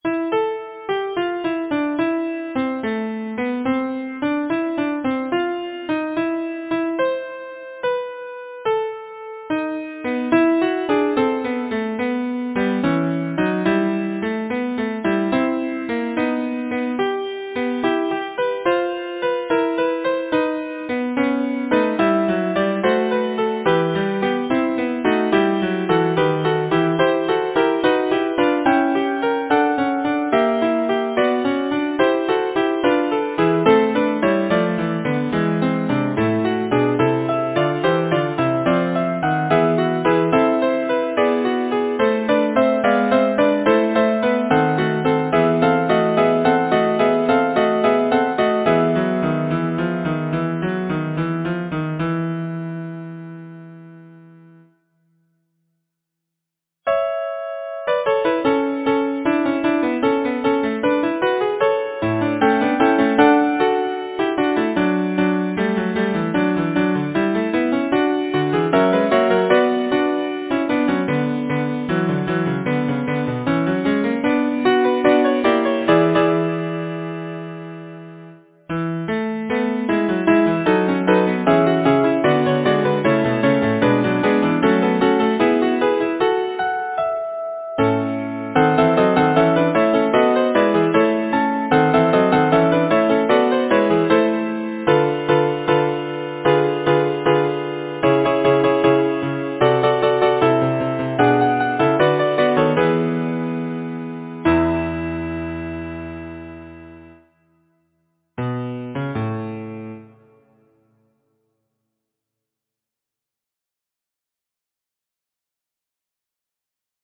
Title: As I Was Going to St. Ives Composer: Obadiah Bruen Brown Lyricist: Number of voices: 4vv Voicing: SATB Genre: Secular, Partsong, Nursery rhyme, Humorous song
Language: English Instruments: A cappella